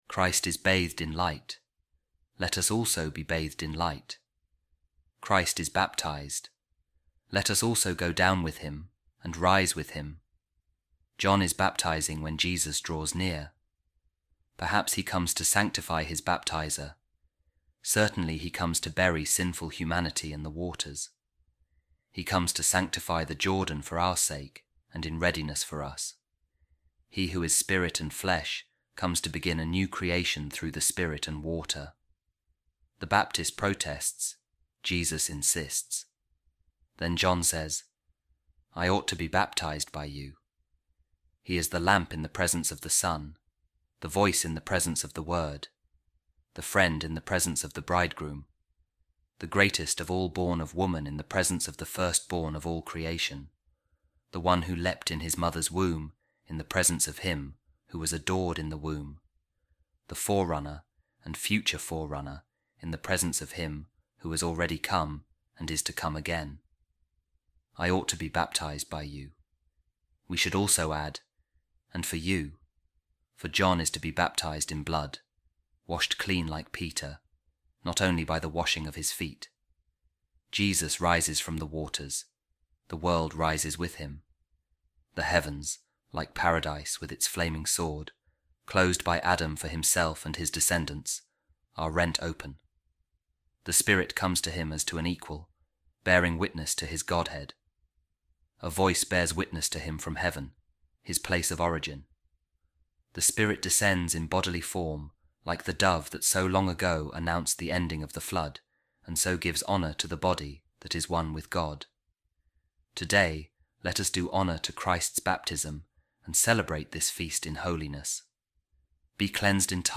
A Reading From The Orations Of Saint Gregory Nazianzen | The Baptism Of Jesus Christ